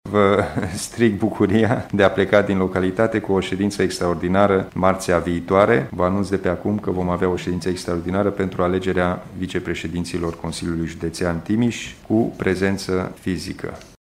Președintele CJ Timiș, Alin Nica.